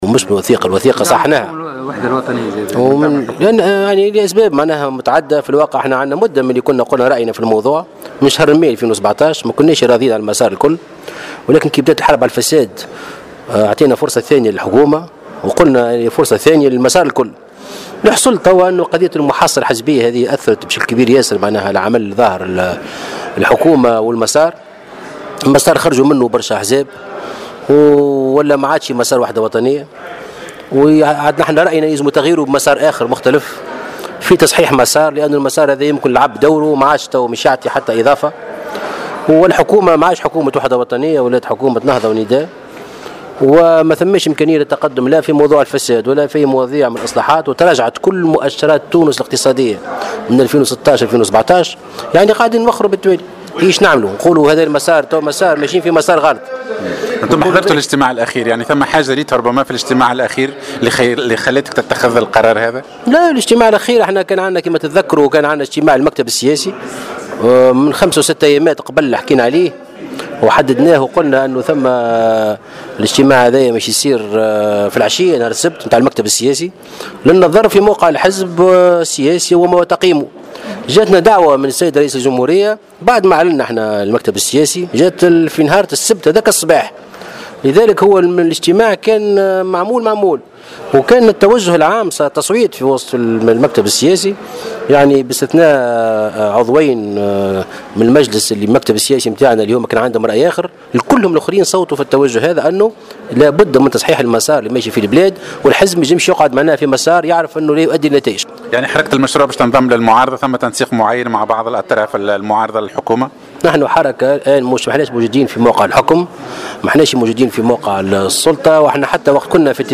وانتقد في تصريح لمراسل "الجوهرة أف أم" عى هامش إشرافه مساء السبت في صفاقس، على اجتماع عام لحزبه بالجهة، ما وصفه بالمحاصصة الحزبية في الحكومة الوحدة الوطنية مضيفا أن هذه الحكومة حكومة النهضة والنداء.